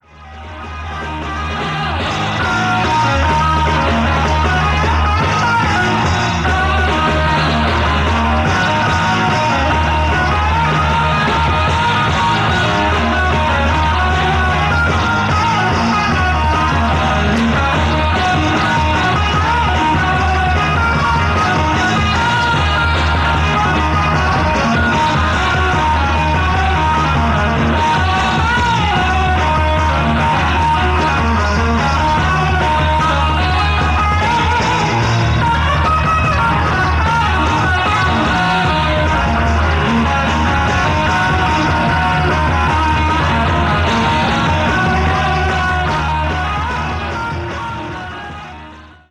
Progressive Rockband
harte Rockmusik